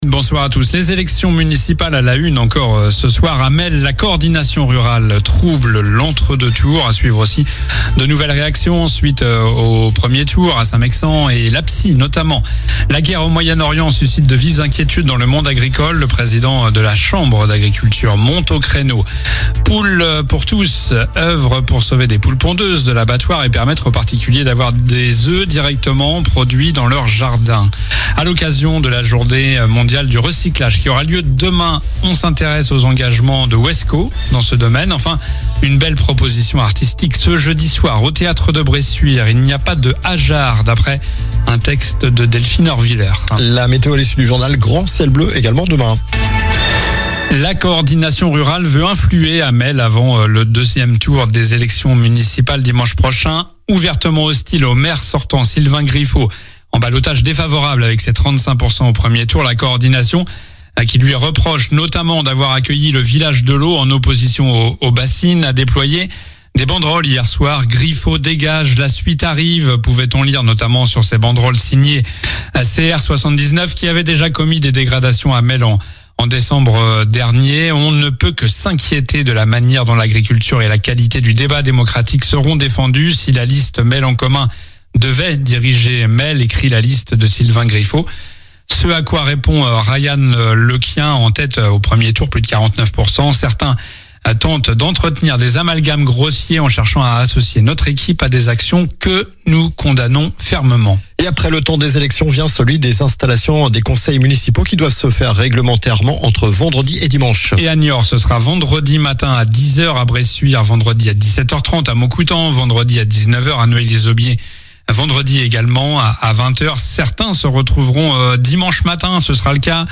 Journal du mardi 17 mars (soir)